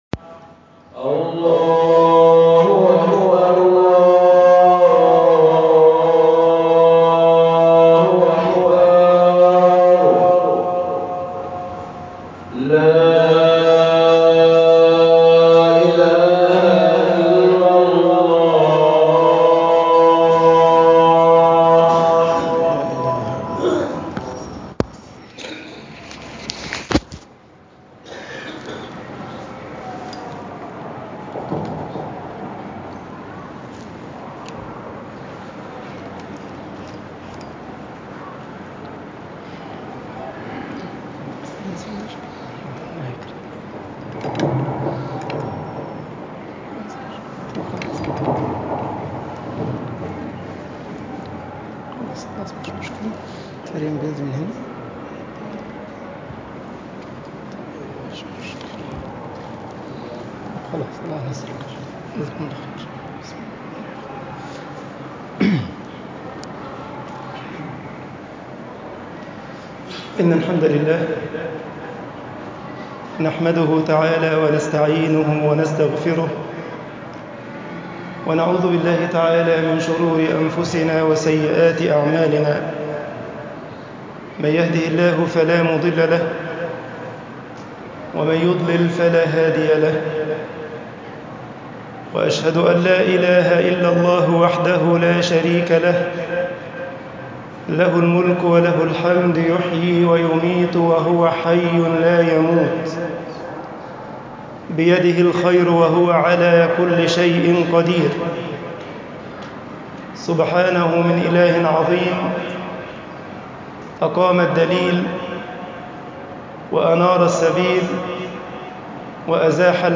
خطب الجمعة - مصر الجمال في الإسلام
khutab aljumaa misr 18_aljamal filislam.mp3